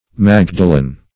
\Mag"da*len\